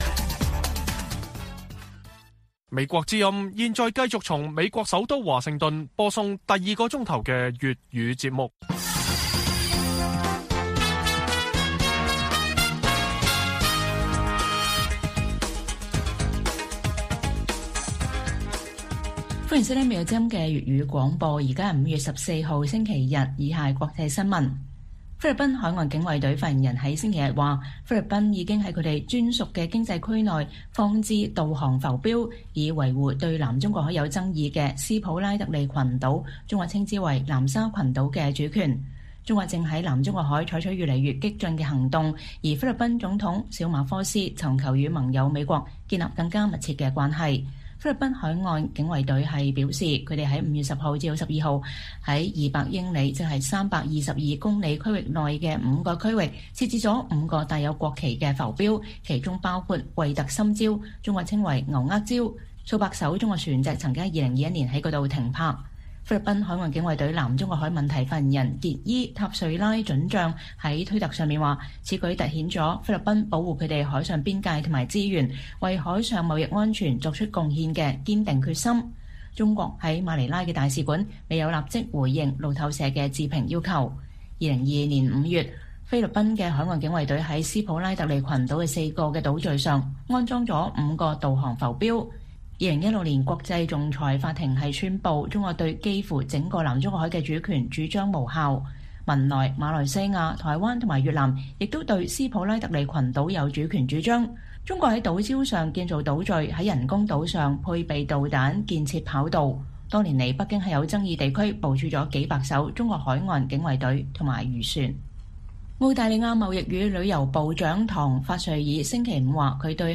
粵語新聞 晚上10-11點: 菲律賓在南中國海部分海域放置浮標以示主權